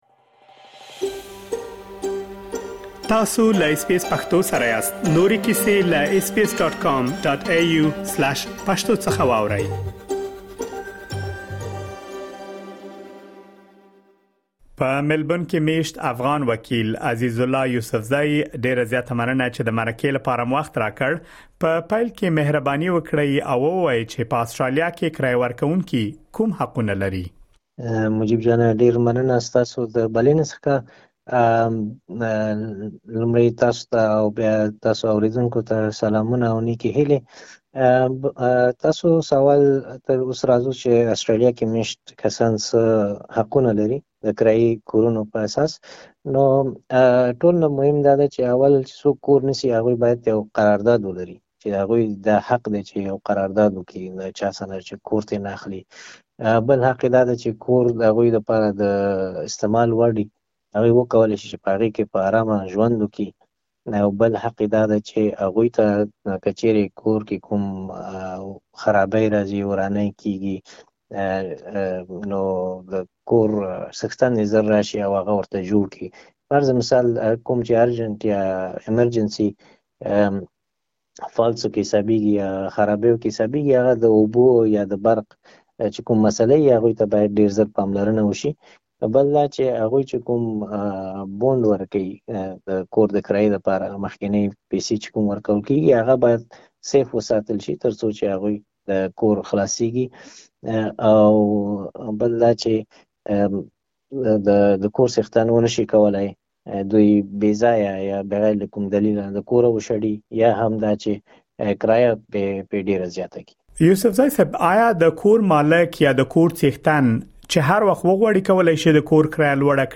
تاسو کولی شئ مهم معلومات په ترسره شوې مرکې کې واورئ.